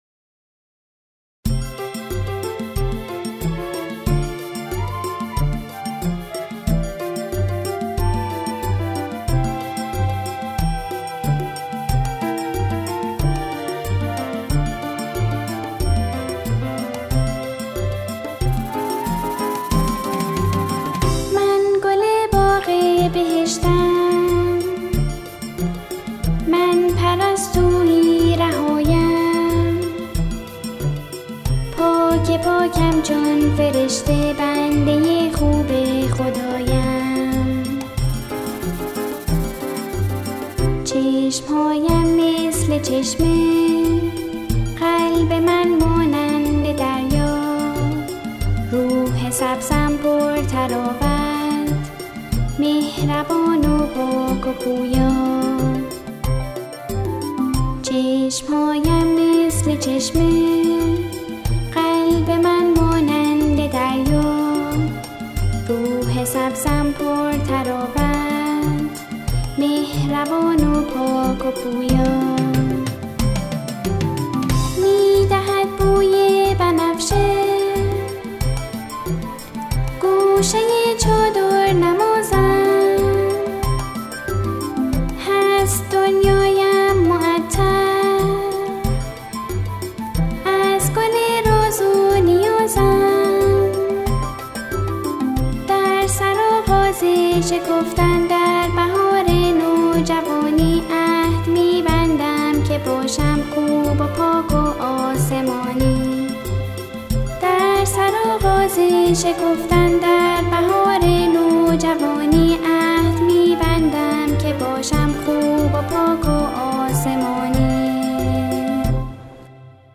تکخوان این قطعه، شعری را درباره جشن تکلیف اجرا می‌کند.